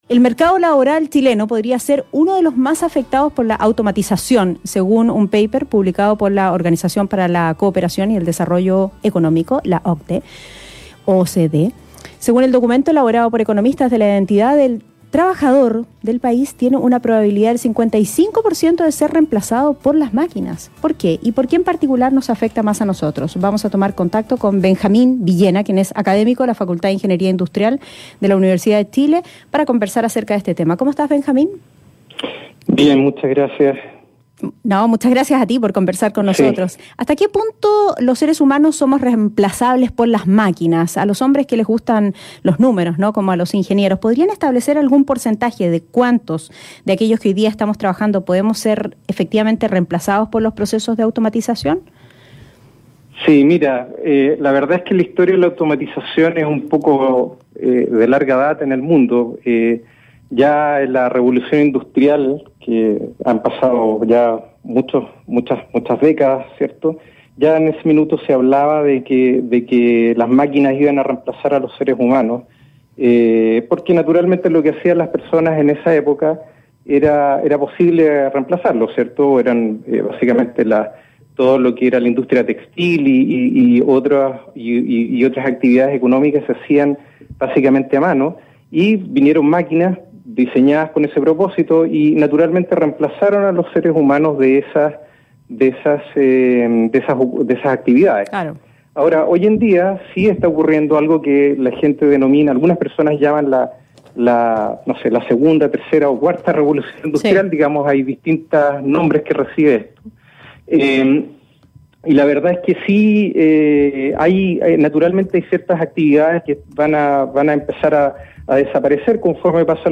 conversó en Radio Infinita sobre cómo la Inteligencia Artificial afectará el Mercado Laboral en Chile.